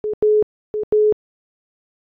Alert_on_call.ogg